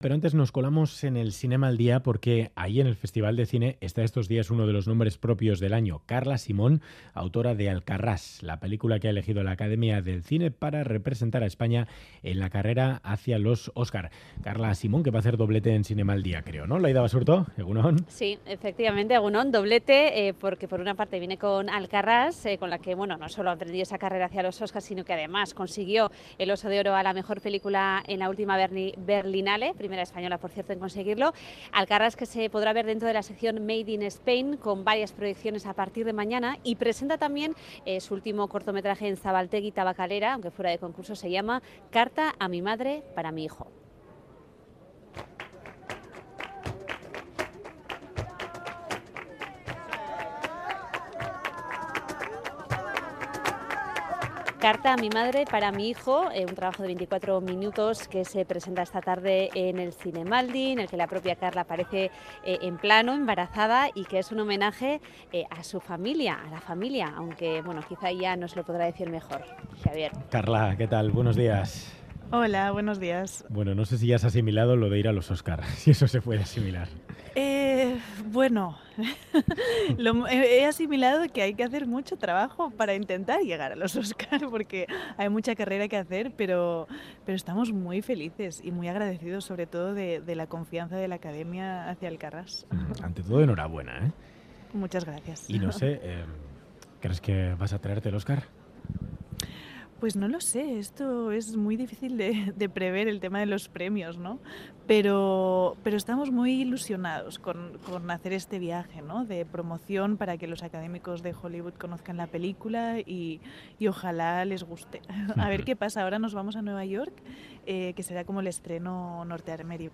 Audio: Trailer de la película Alcarrás de Carla Simón entrevistada en Radio Euskadi